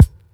kits/RZA/Kicks/WTC_kYk (11).wav at main